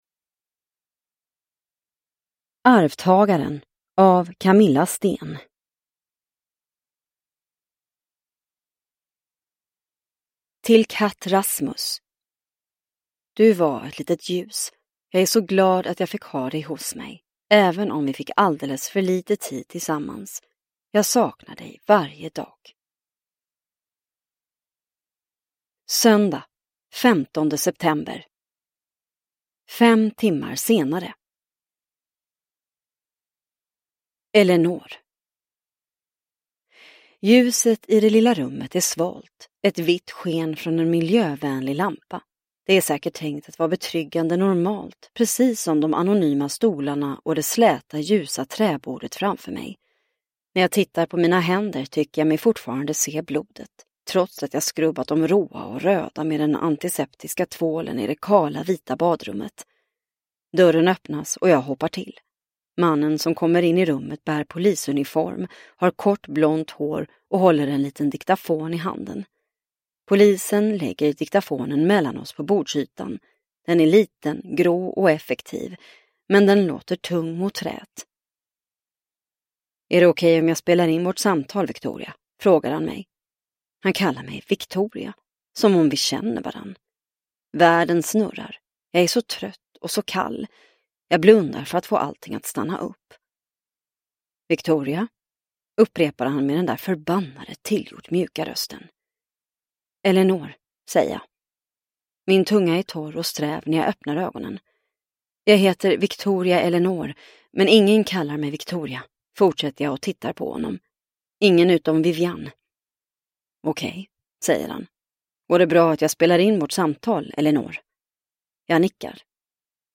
Arvtagaren – Ljudbok – Laddas ner